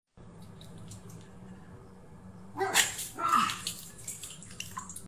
Satisfying AI ASMR Pimple Popper! Sound Effects Free Download
Satisfying AI ASMR Pimple Popper! sound effects free download